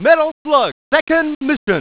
押すと、「METAL SLUG 2ND MISSION」　と叫ぶぞ!!!